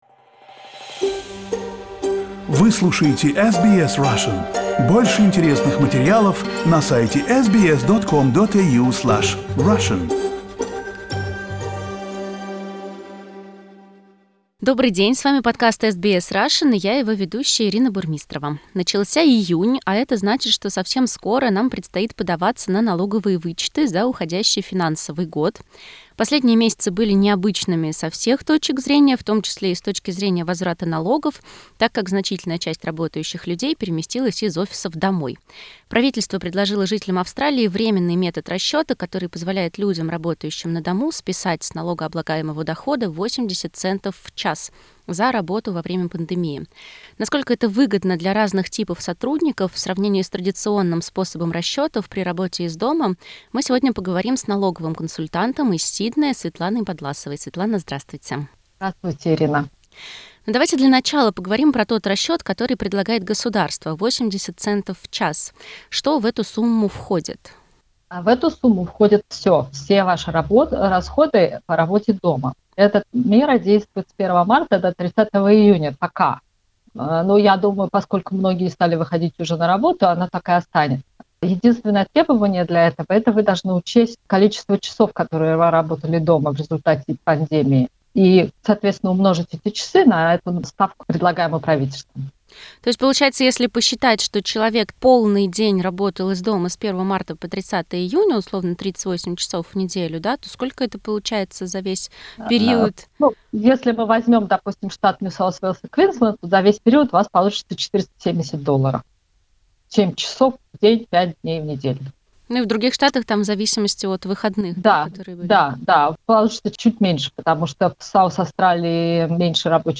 Главное в этом интервью